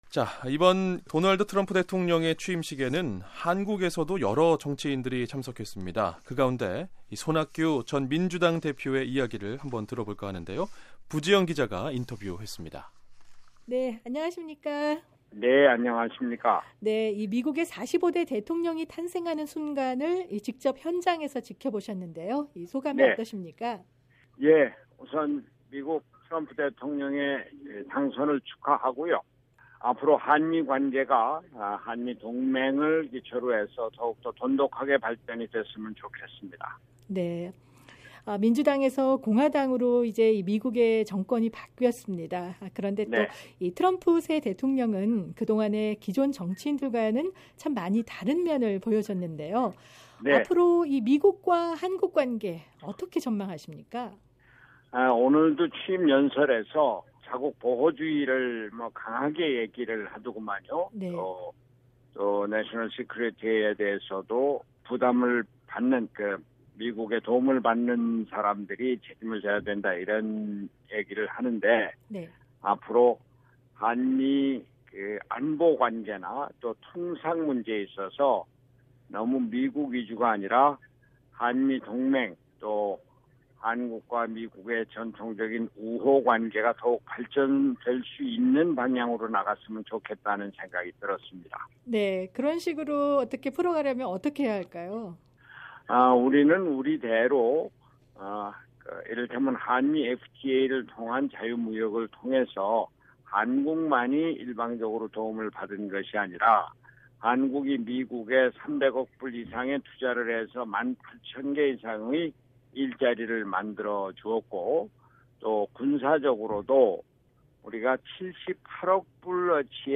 [인터뷰 오디오: 손학규 전 민주당 대표] 트럼프 취임식 참석 소감, 미-한 관계 전망